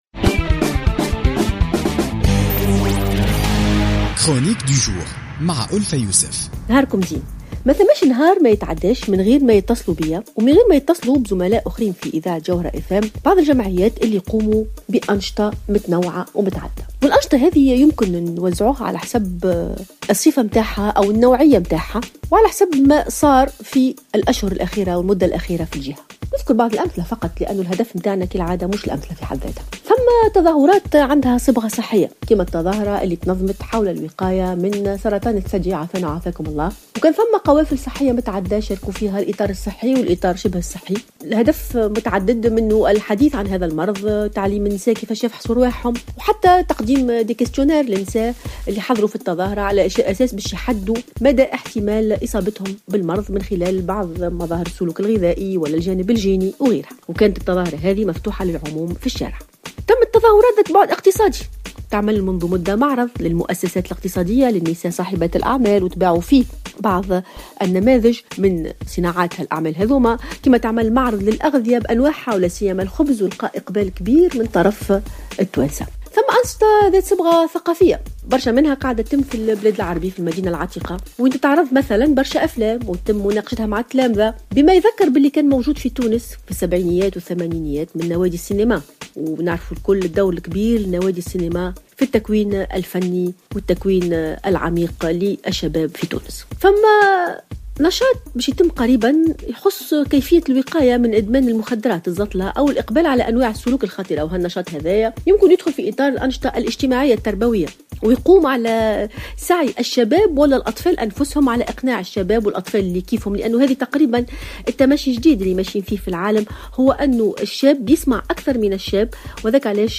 تحدثت الباحثة ألفة يوسف في افتتاحية اليوم الجمعة 6 ماي 2016 عن مساهمة المجتمع المدني ممثلا في الجمعيات التي تقوم بأنشطة مختلفة وفي مجالات متعددة في إصلاح البلاد.